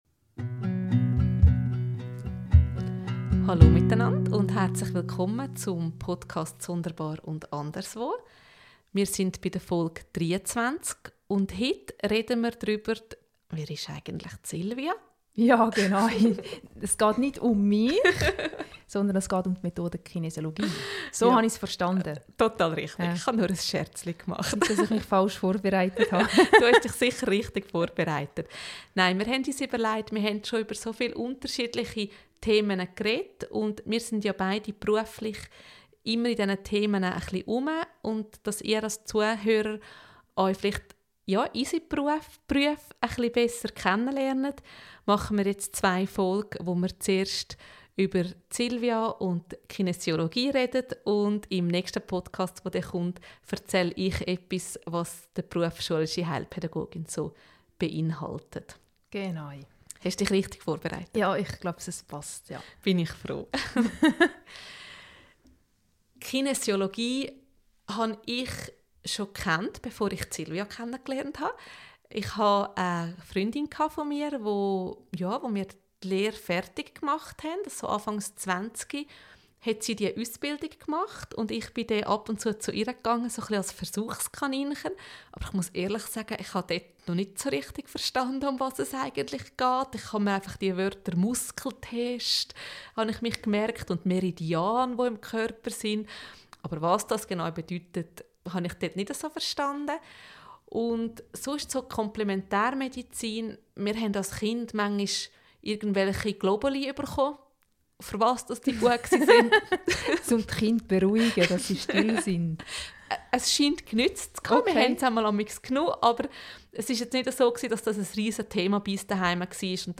Kinesiologie ist kein Ersatz, sondern eine Ergänzung zur Schulmedizin – und sie kann helfen, das innere Gleichgewicht wiederzufinden. Ein Gespräch über Vertrauen, Selbstwahrnehmung und den Mut, den eigenen Körper wieder als Verbündeten zu sehen.